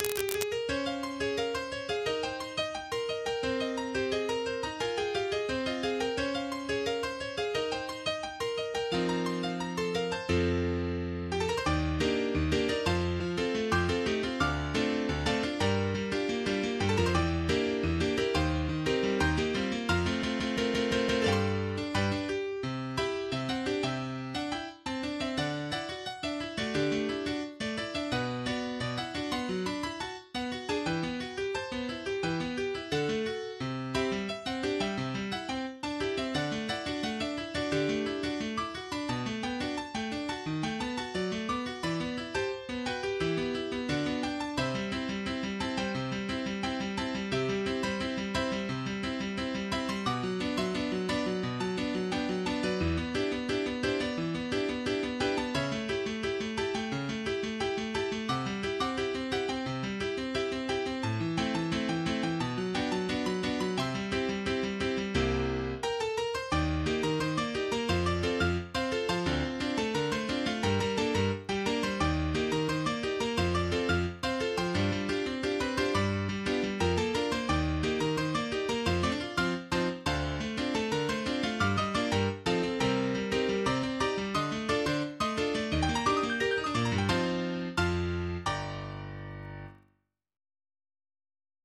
MIDI 10.04 KB MP3 (Converted) 1.4 MB MIDI-XML Sheet Music
pop song